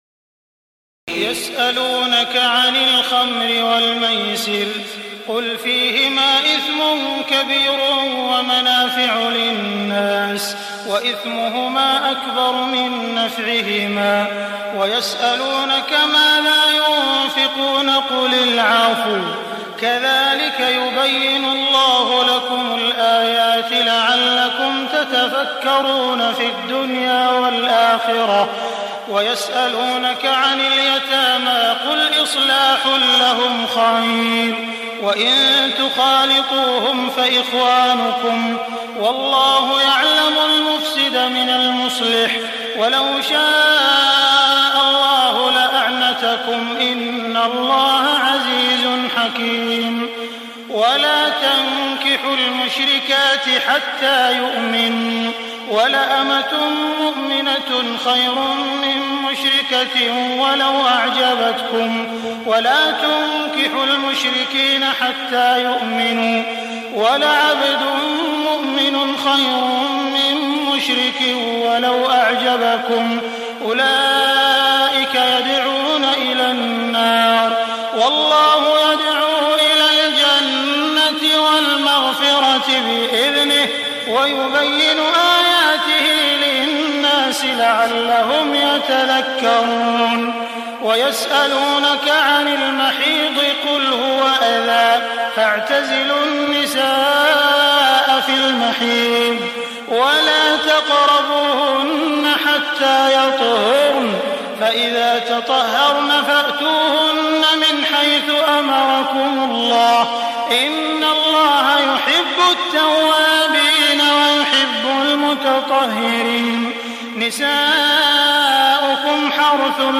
تهجد ليلة 22 رمضان 1423هـ من سورة البقرة (219-252) Tahajjud 22 st night Ramadan 1423H from Surah Al-Baqara > تراويح الحرم المكي عام 1423 🕋 > التراويح - تلاوات الحرمين